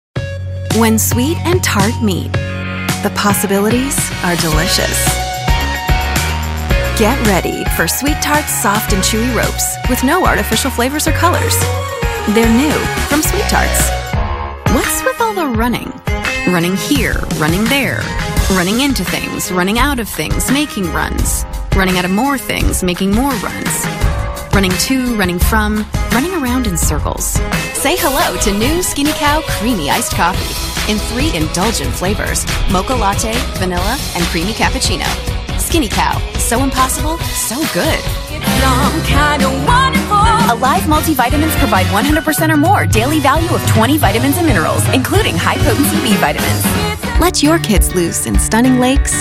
She has voiced everything from promos to cartoons to video games and narration.
Commercial - EN